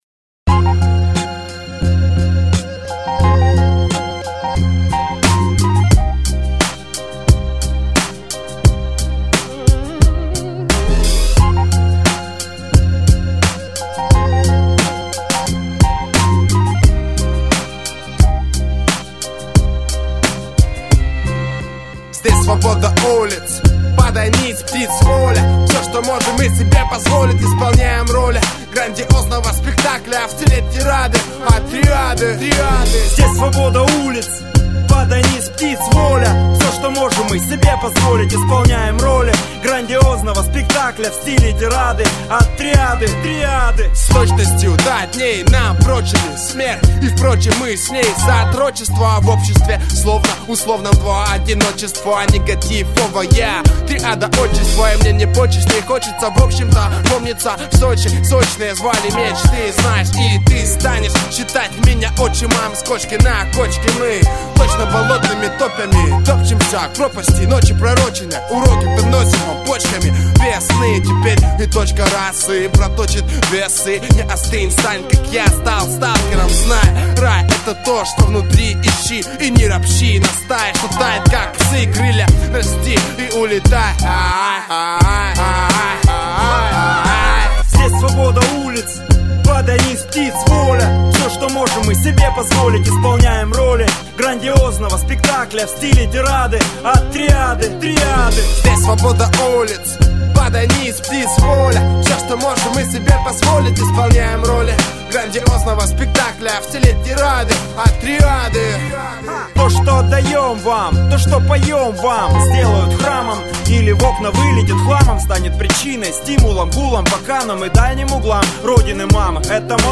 Назад в ~РЭП~